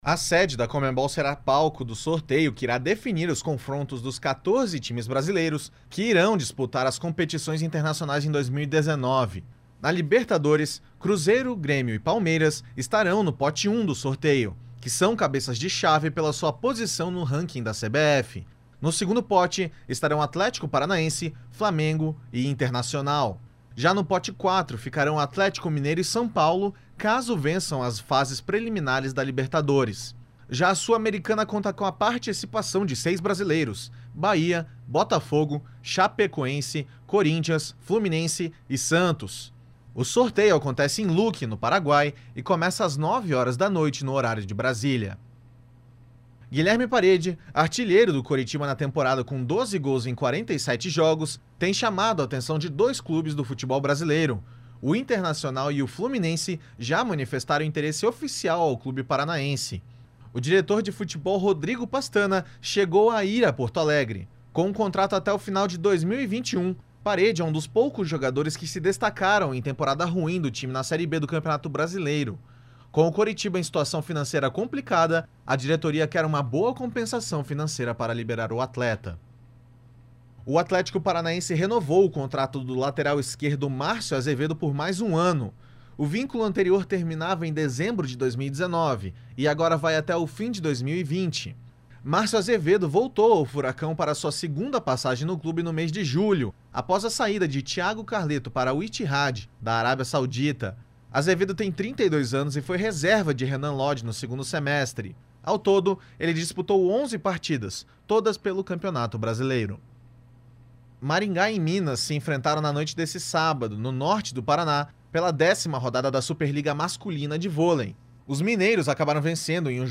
GIRO ESPORTIVO – 17.12 – SEM TRILHA